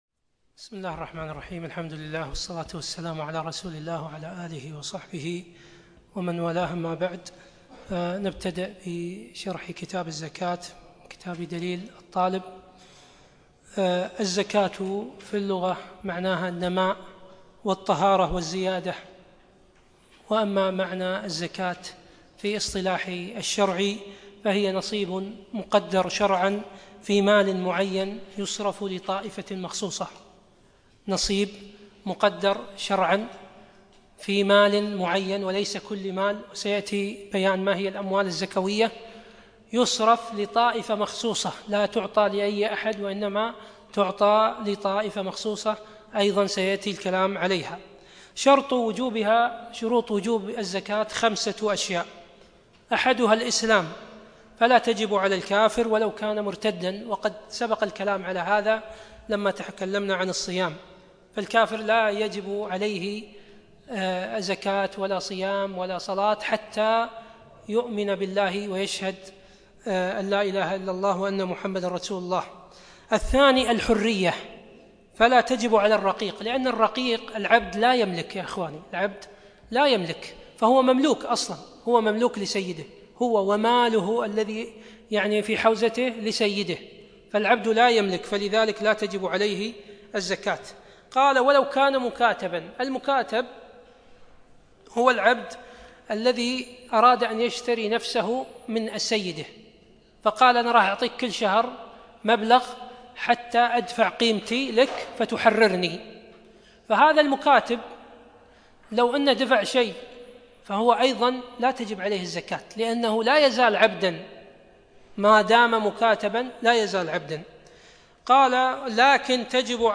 يوم الاربعاء 25 شعبان 1437هـ الموافق 1 6 2016م في مسجد عايض المطيري الفردوس